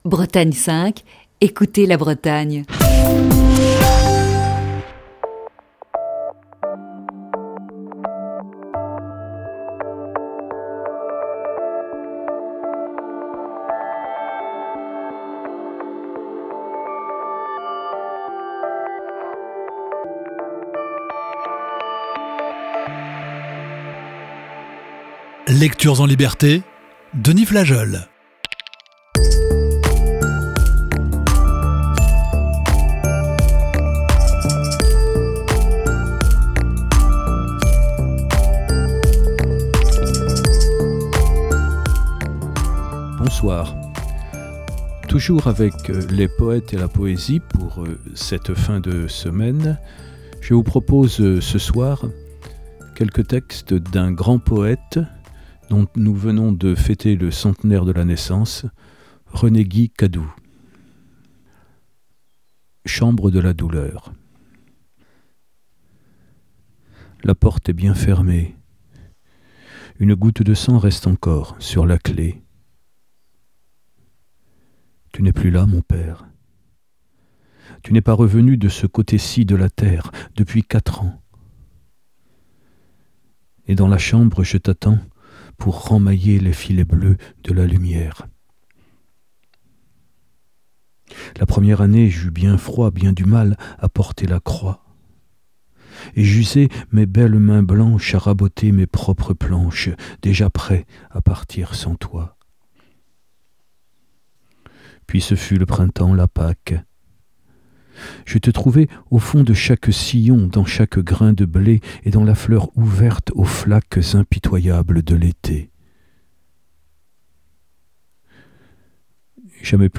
Émission du 20 février 2020.